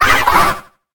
Cri de Nigosier dans Pokémon HOME.